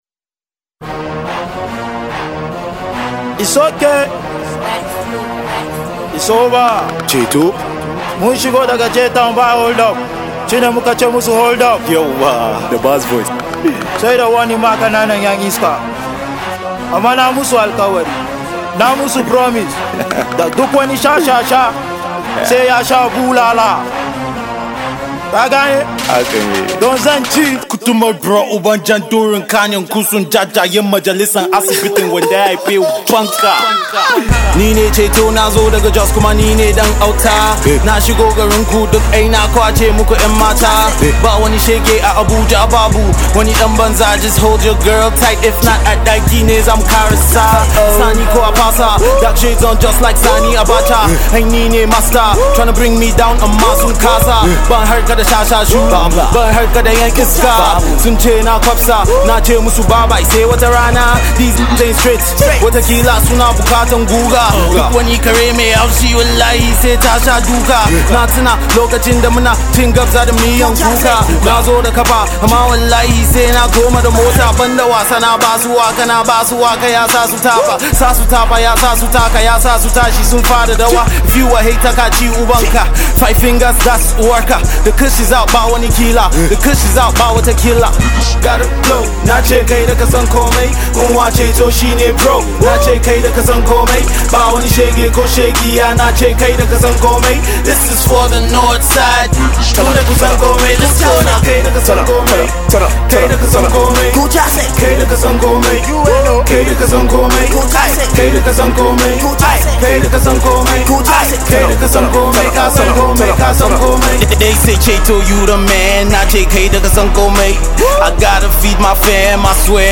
Hausa Rap
Freestyle